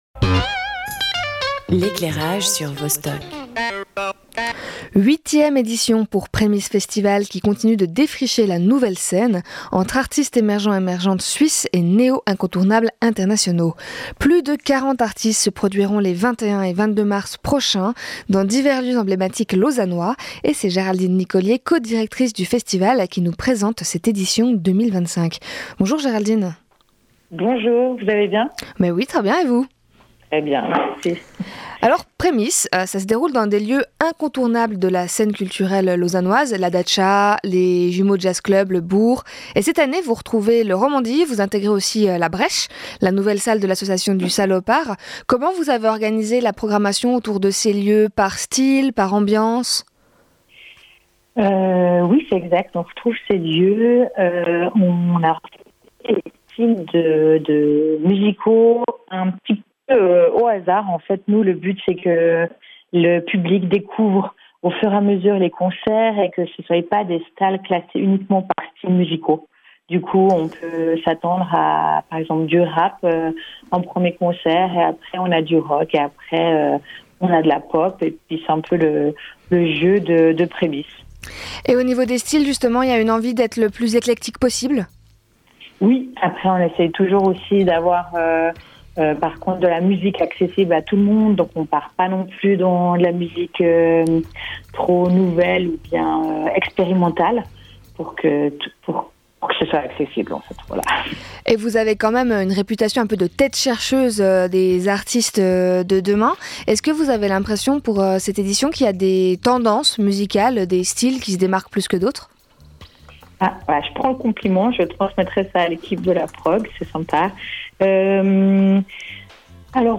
Première diffusion antenne : 4 février 2025